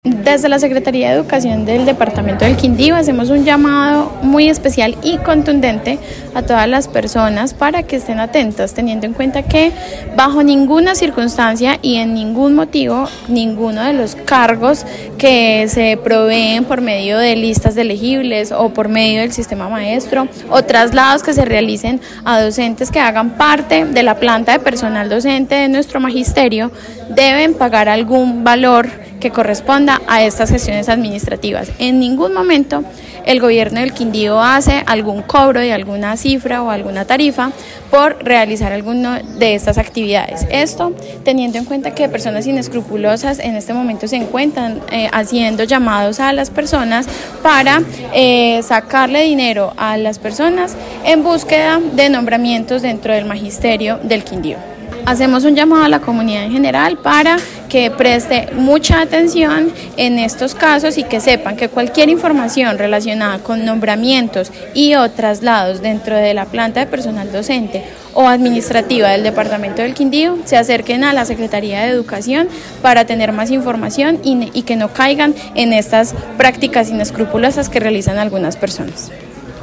Tatiana Hernández, secretaria de educación del Quindío